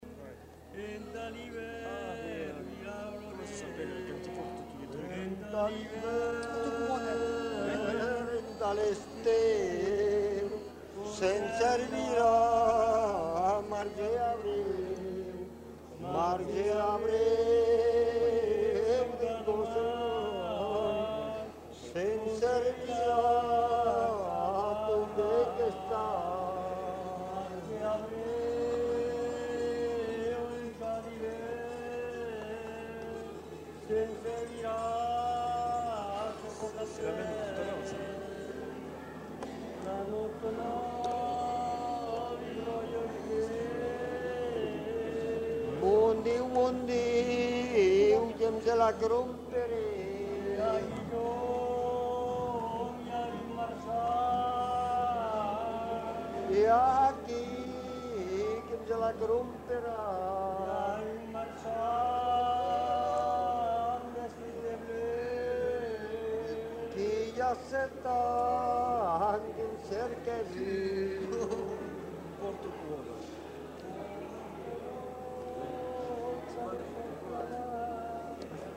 Lieu : Saint-Sever
Genre : chant
Type de voix : voix d'homme
Production du son : chanté
Notes consultables : Les deux hommes chantent en alternance.